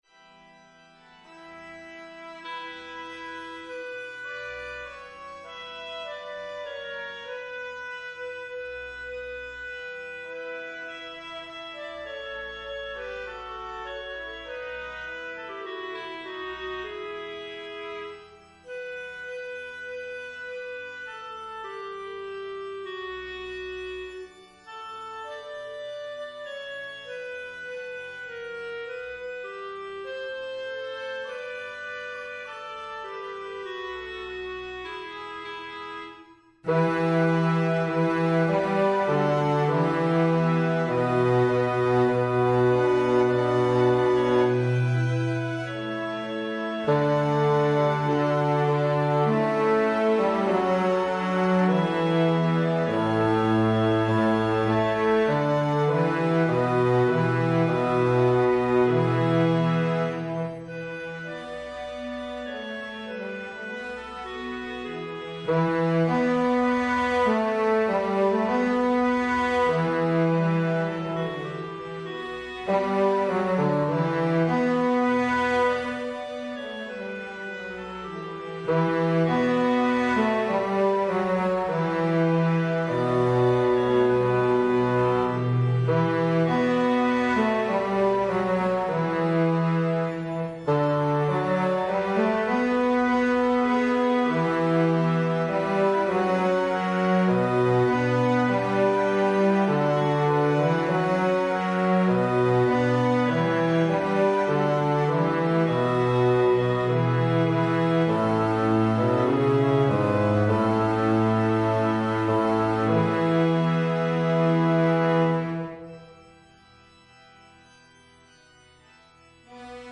Bass
Evensong Setting